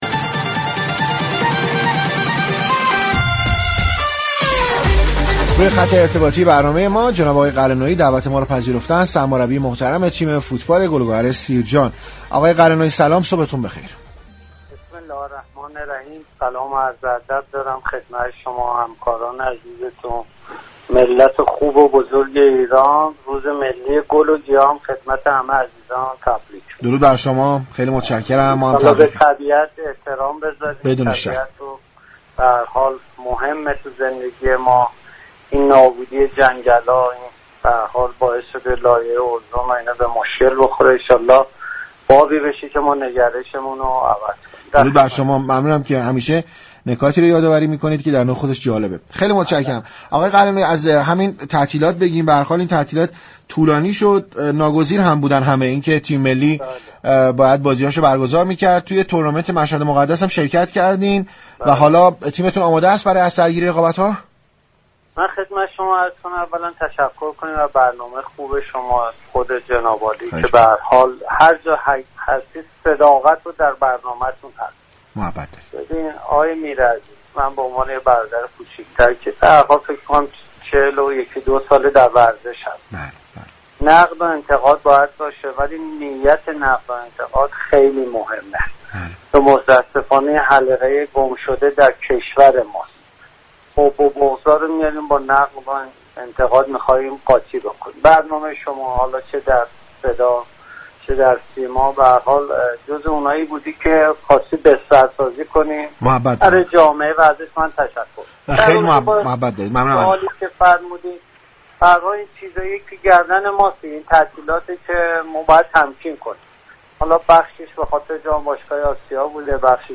به گزارش پایگاه اطلاع رسانی رادیو تهران، امیر قلعه نویی در گفتگو با برنامه تهران ورزشی گفت: محور گم شده كشور ما برنامه‌ریزی است در ایران است كه به روزمرگی رسیده‌ایم و هربار برای خودمان مساله‌ای را به وجود می‌آوریم باید بپذیریم كه برنامه ریزی نداریم و این بی برنامگی باعث شده كه ما لطمه بخوریم و متاسفانه در سال های اخیر در تمام ابعاد از جمله ورزش این مساله مشكل ساز شده است.